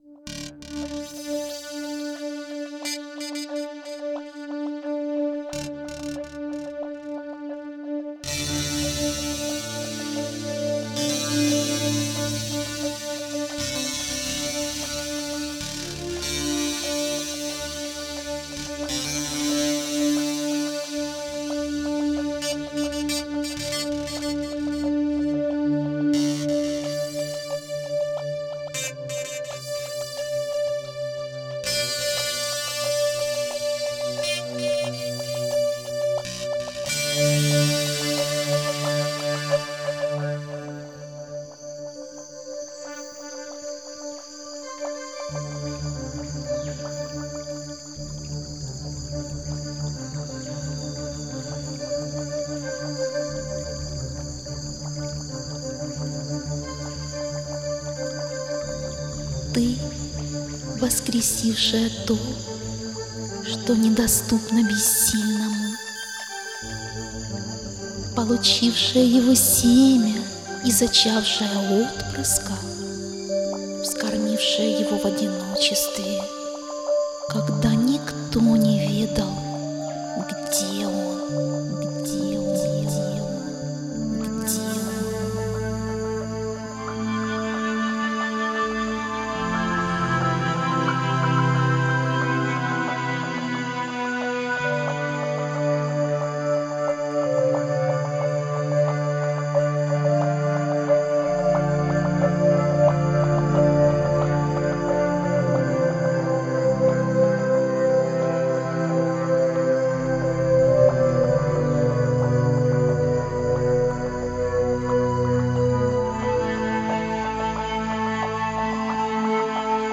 Медитативная музыка Духовная музыка Мистическая музыка